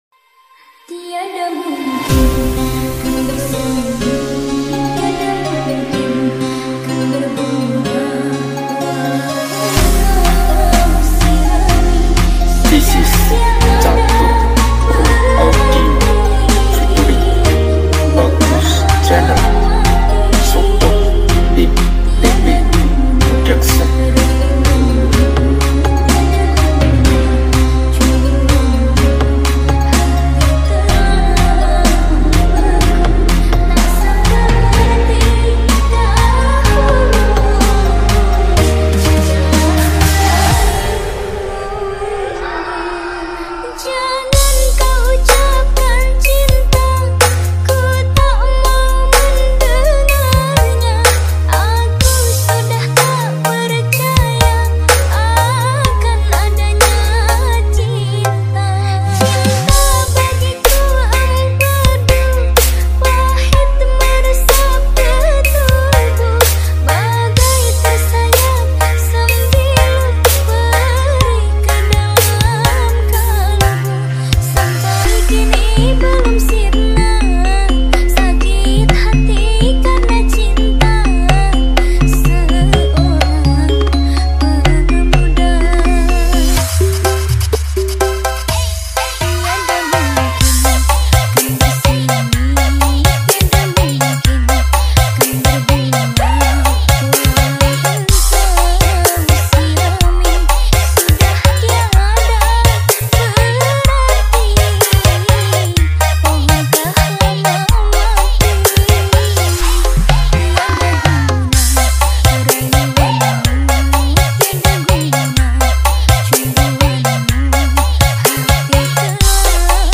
battle bass